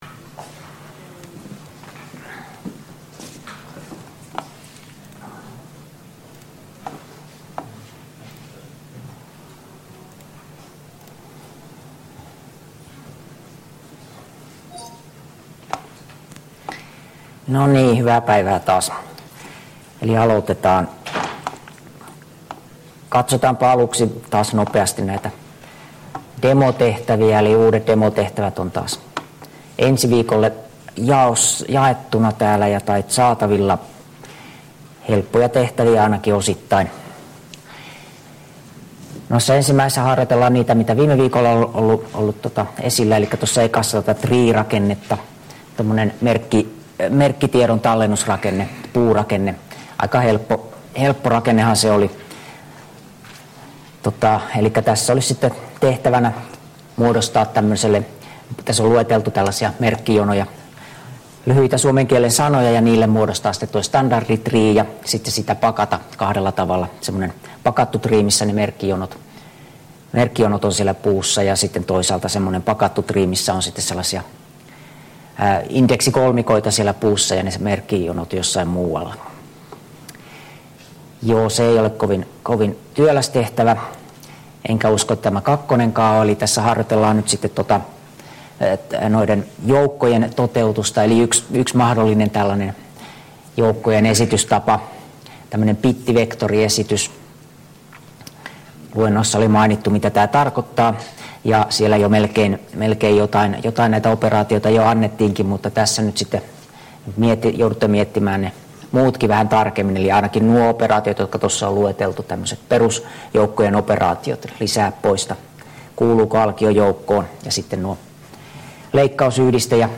Luento 7 — Moniviestin